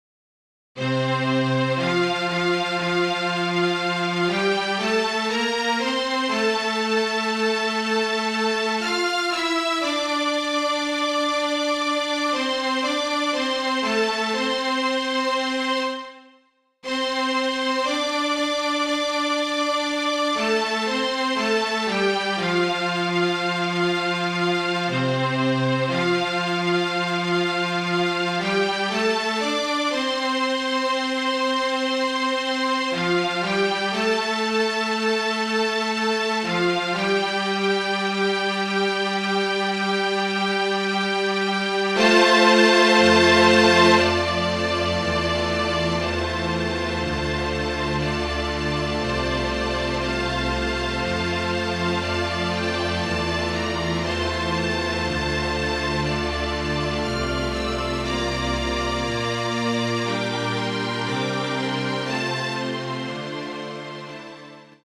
Flute, Violin and Cello (or Two Violins and Cello)
MIDI